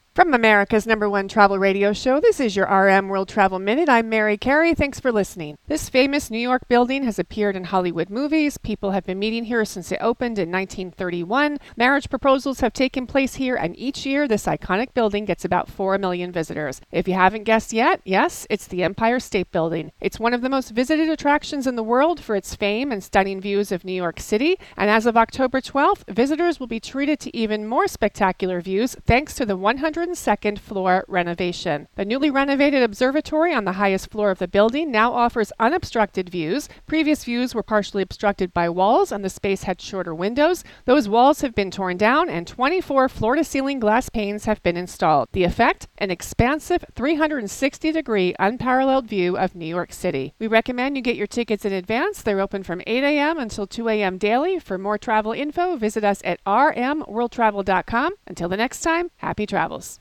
America's #1 Travel Radio Show
Host